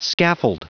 Prononciation du mot scaffold en anglais (fichier audio)
Prononciation du mot : scaffold